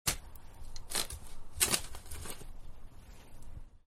Shovel2.wav